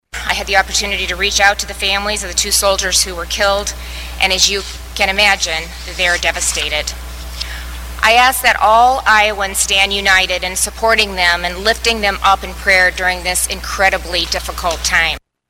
Iowa Governor Kim Reynolds and Iowa National Guard Major General Stephen Osborn held a joint news conference following the ISIS ambush attack in Syria that killed two Iowa soldiers and left three others wounded.
The news conference was held Saturday night.